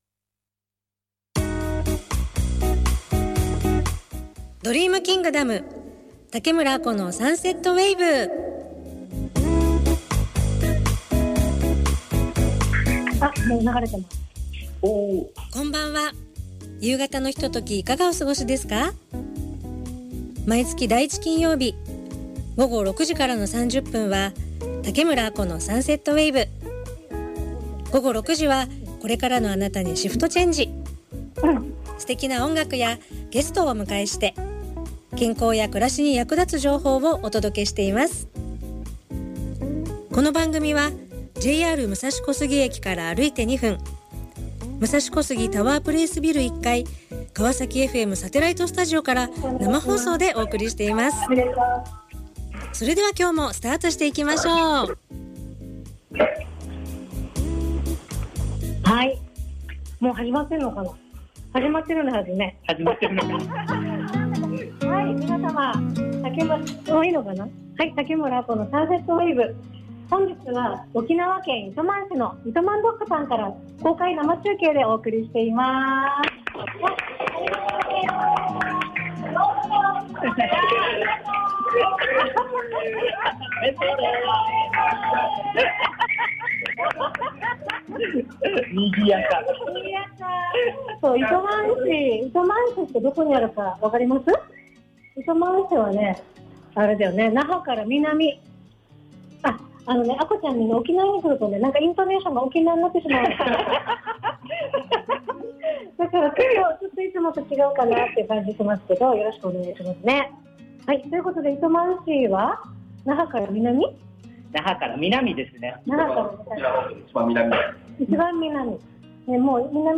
第16回は沖縄から生放送！糸満市「イトマンドッグ」さんをお借りして放送しました。